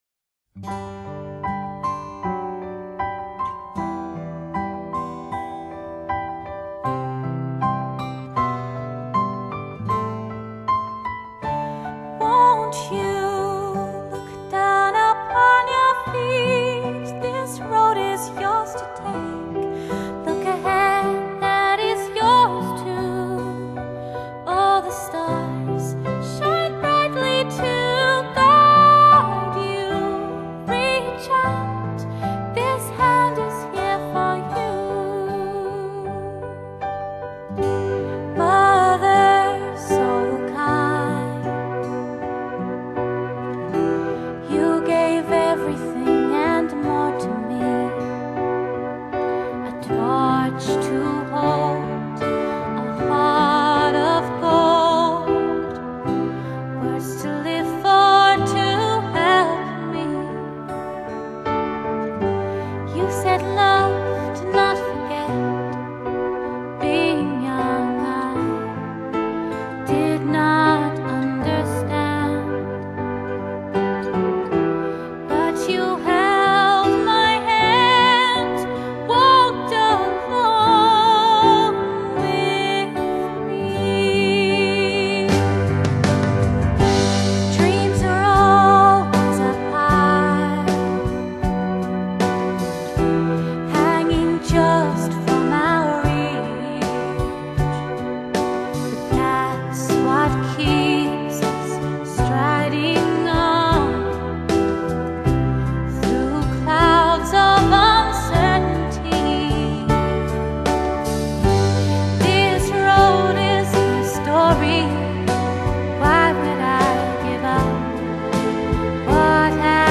Genre: Classical Crossover
新西兰女高音